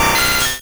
Cri de Scarabrute dans Pokémon Rouge et Bleu.